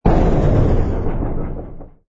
engine_bw_freighter_kill.wav